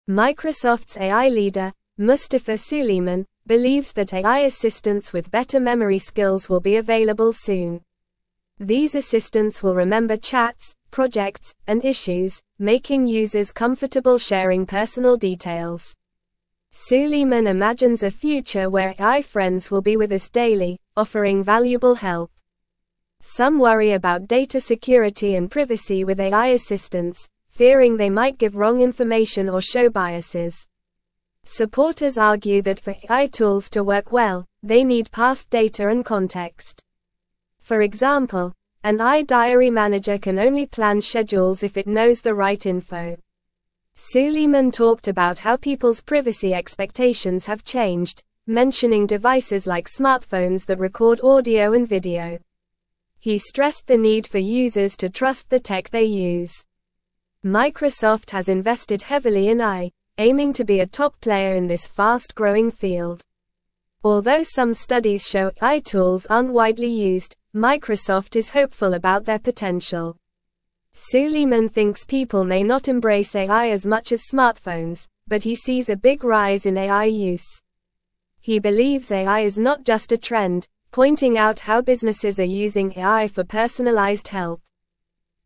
Normal
English-Newsroom-Upper-Intermediate-NORMAL-Reading-Microsofts-Head-of-AI-Predicts-Advanced-Memory-Assistants.mp3